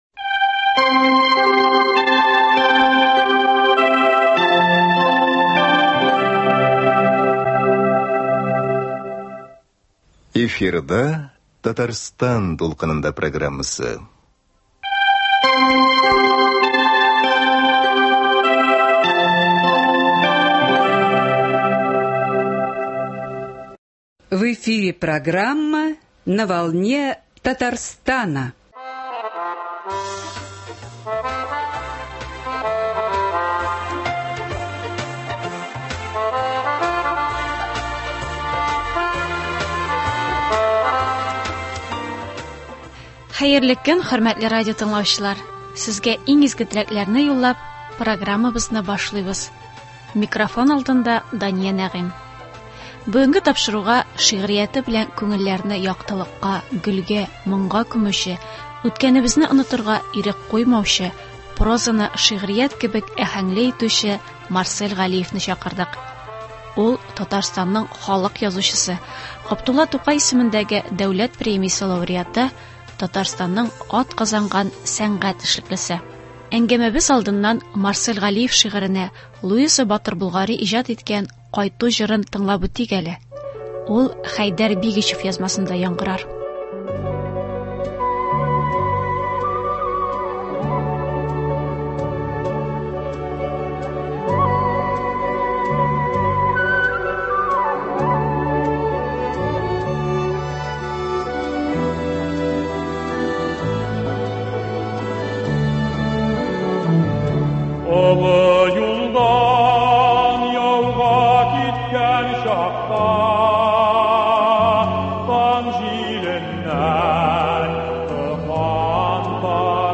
Тапшыру кунагы – шигърияте белән күңелләрне яктылыкка, гөлгә, моңга күмүче, үткәнебезне онытырга ирек куймаучы, прозаны шигърият кебек аһәңле итүче